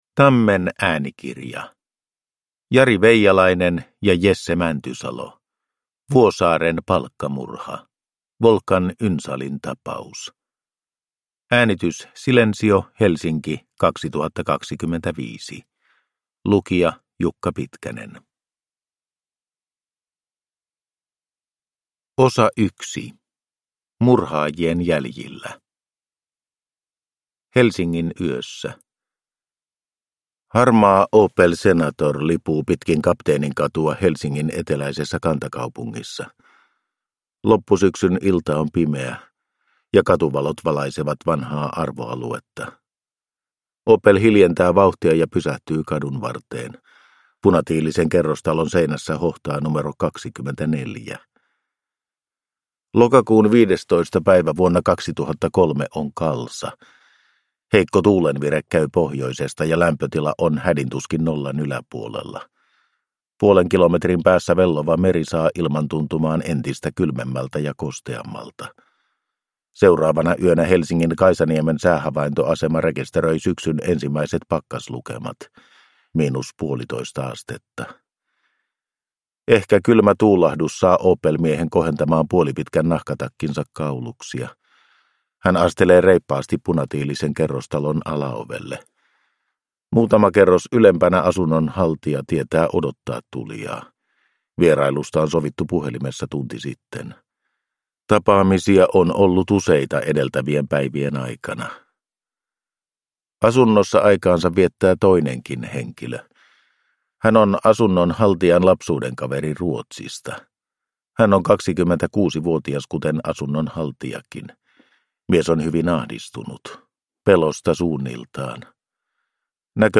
Vuosaaren palkkamurha – Ljudbok – Digibok